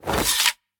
retract1.ogg